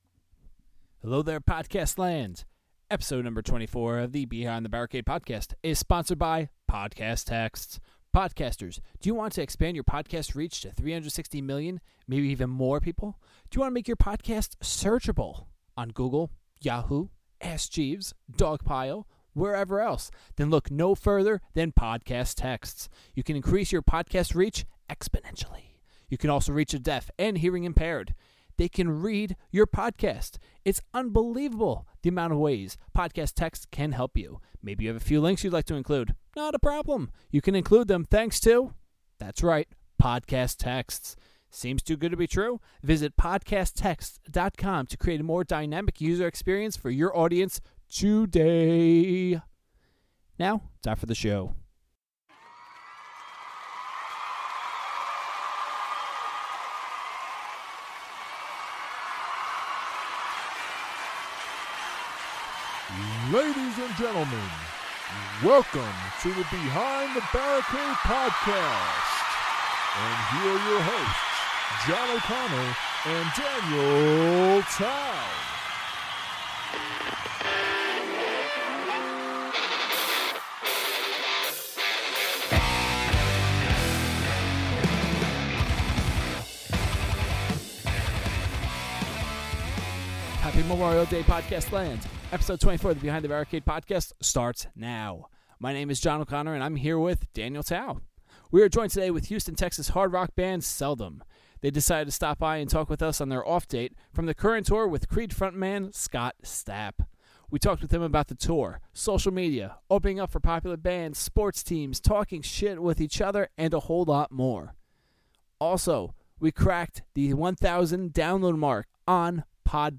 Episode 24 features Houston hard rock band Seldom! We talk with them about being on tour with Creed front man Scott Stapp, social media, sports, talking smack about each other and a whole lot more!